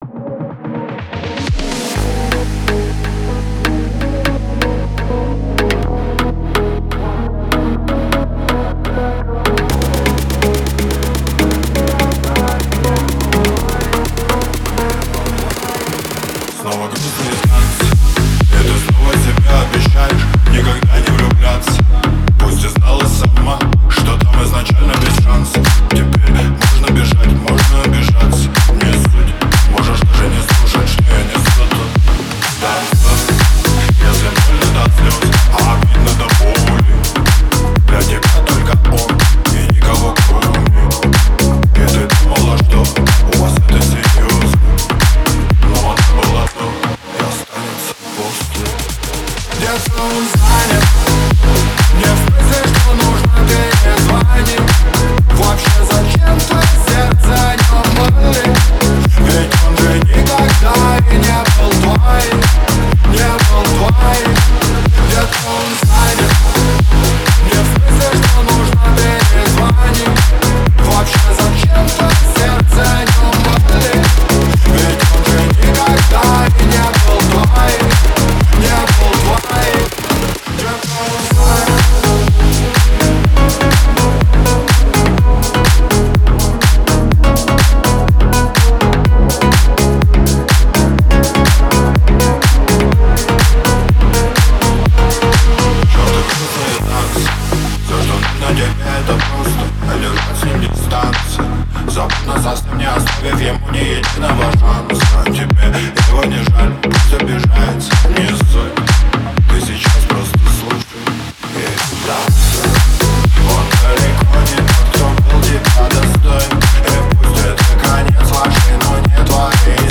скачать Поп